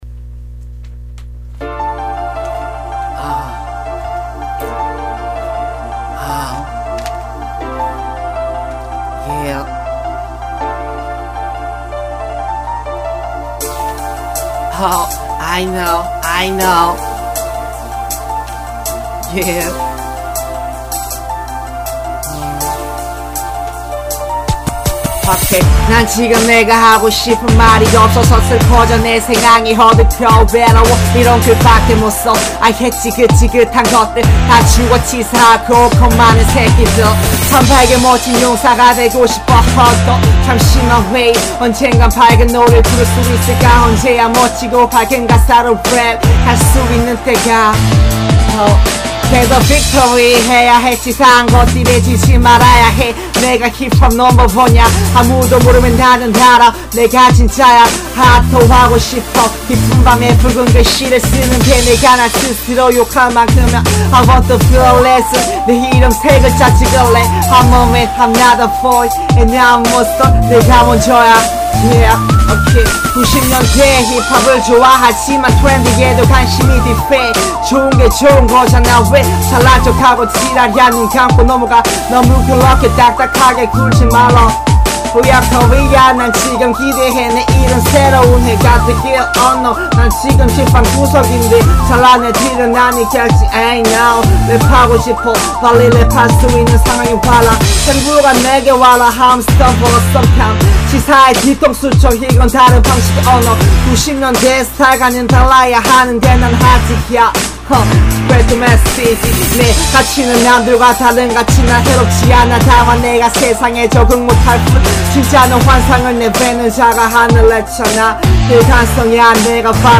무제no.10(이상하고 성의없는 랩) | 리드머 - 대한민국 힙합/알앤비 미디어
오랜전에 주제없이 썻던글에 비트틀어놓고 느낌가는데로 랩해봤습니다성의는 없지만 나름대로 듣는 재미가 있는거 같아서올립니다~이상하지만 끝까지 들어주세요~
믹싱의 문제인지, 가사가 잘 들리지 않습니다!!